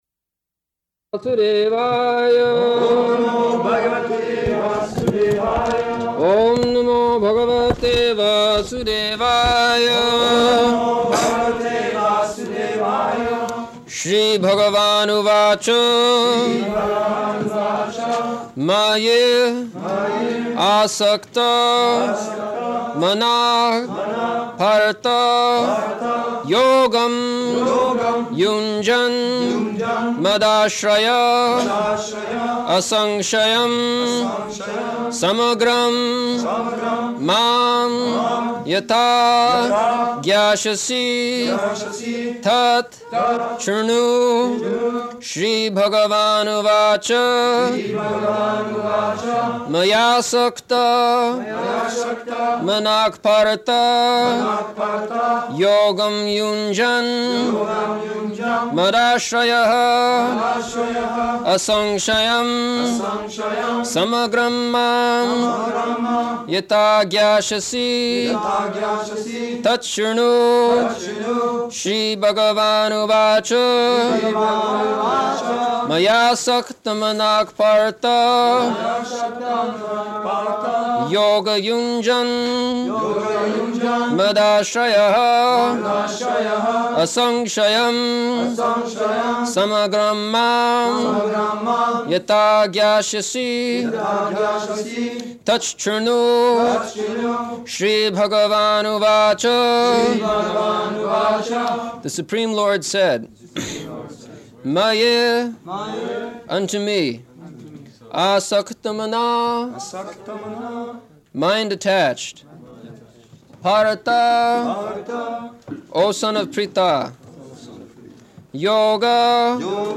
June 13th 1974 Location: Paris Audio file
[translated into French throughout]
[devotees repeat] [leads chanting of verse, etc.]